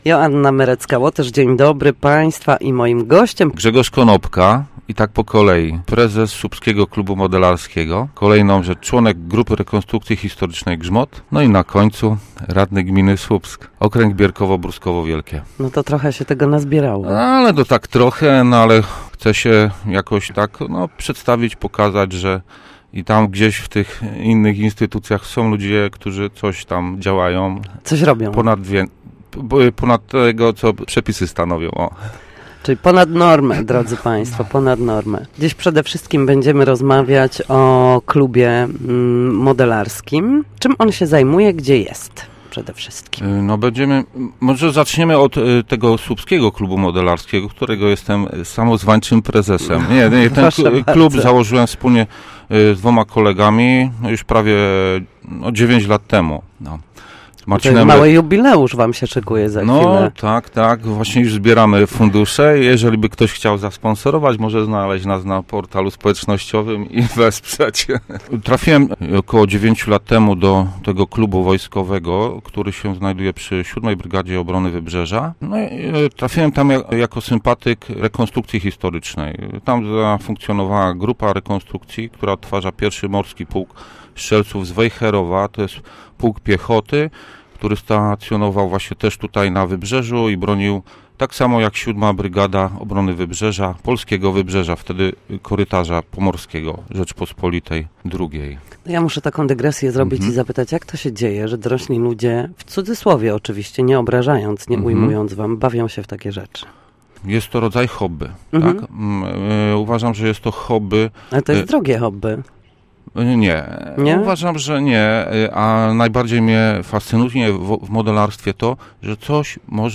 Gość Studia Słupsk